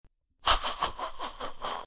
Download Goblin sound effect for free.
Goblin